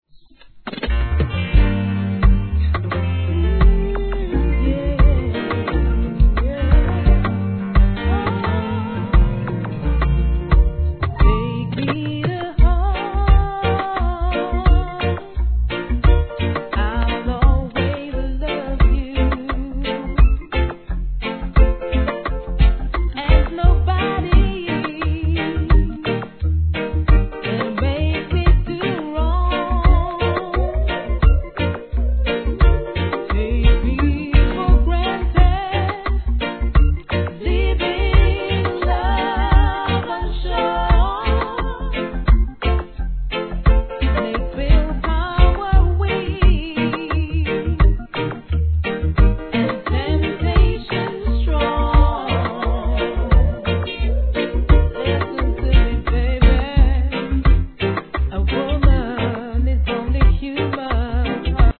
REGGAE
心地よく弾むRHYTHMで見事なコーラスがマッチする2001年作品!